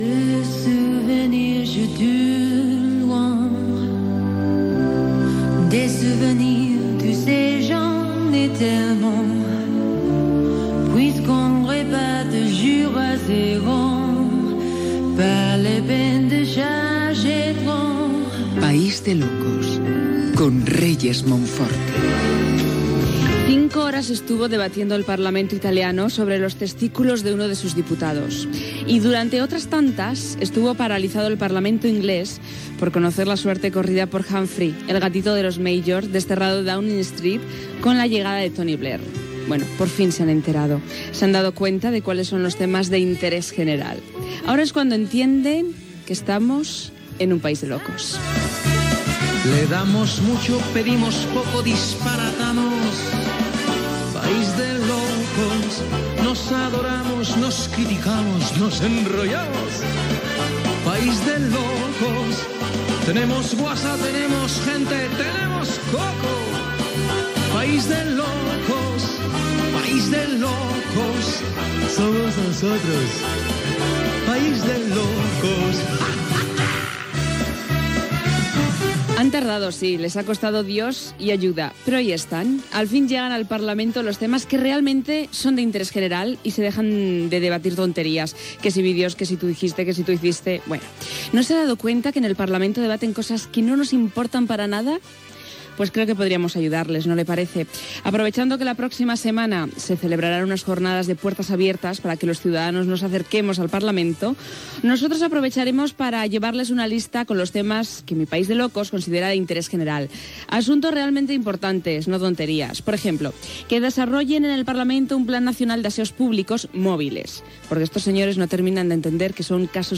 Identificació i inici del programa
Entreteniment